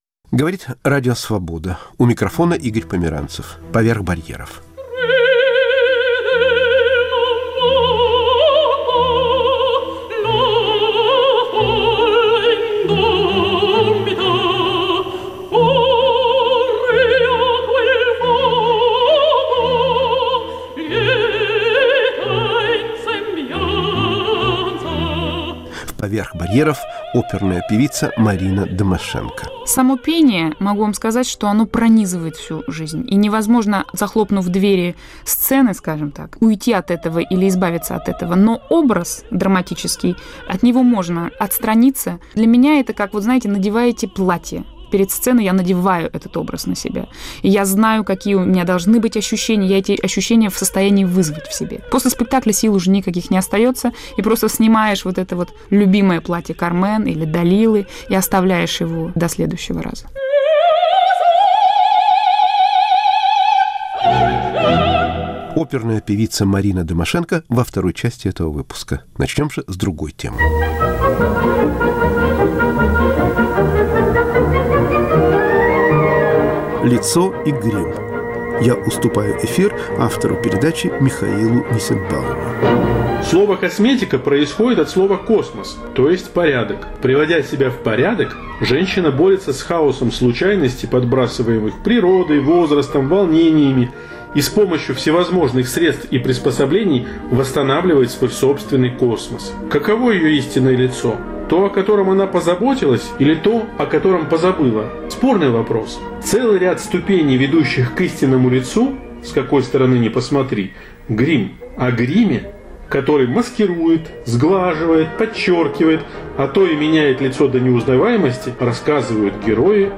Волонтёр из Тольятти рассказывает о бездомных и тех, кто им помогает *** Фонограммархив Пушкинского дома. Редкие записи голосов русских крестьян, Льве Толстого, Бориса Шергина и др.